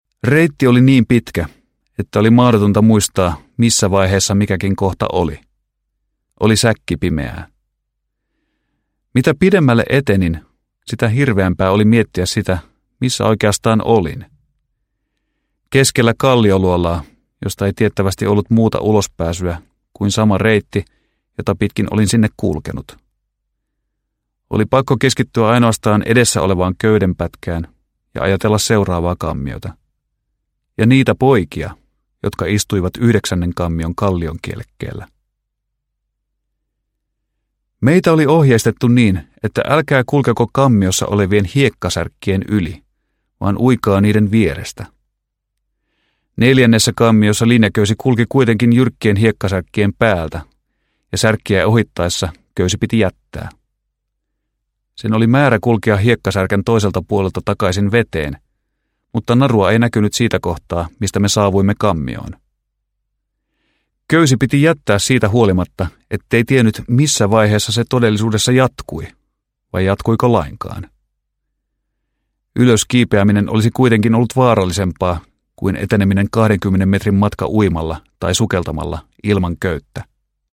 Sukellus valoon – Ljudbok – Laddas ner